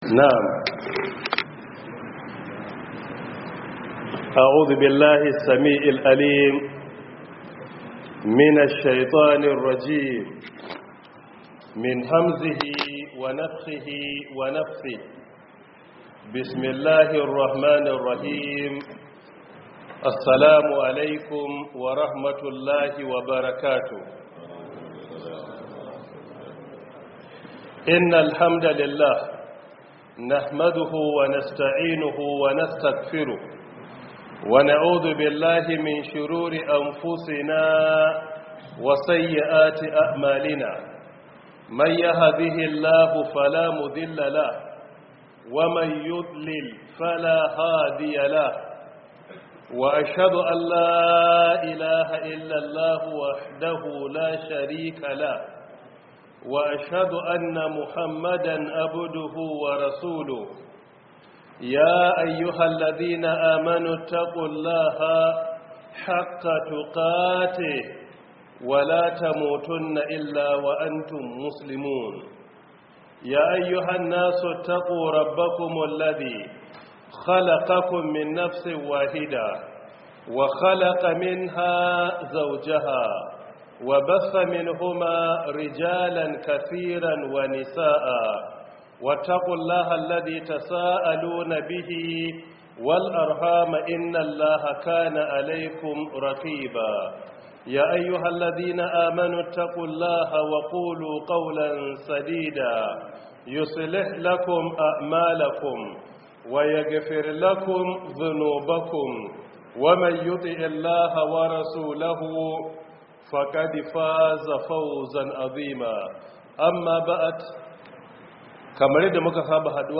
Audio lecture by Professor Isa Ali Ibrahim Pantami — 1447/2026 Ramadan Tafsir